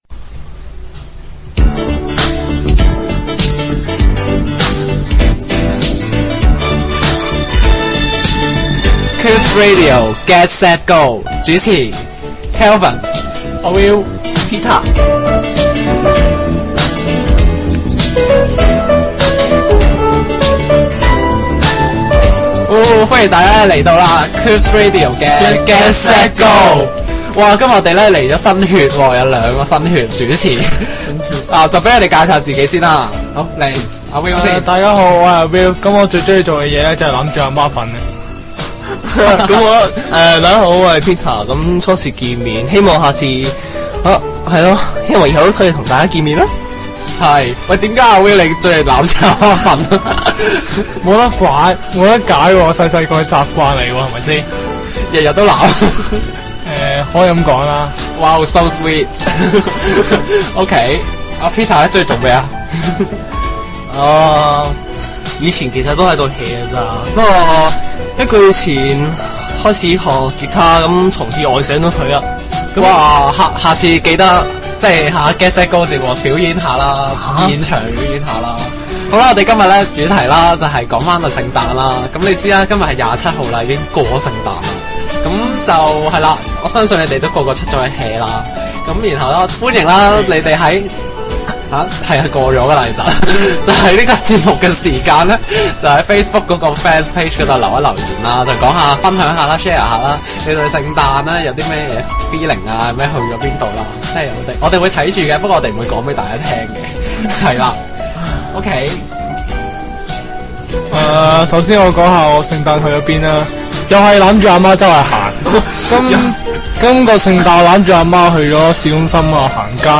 《CubXRadio Get-Set-Go》是 CubX 製作的電台清談節目，在2011年12月27日首播。節目由音樂、電影、生活，以至社會熱話，無一話題不談，為聽眾提供最新資訊。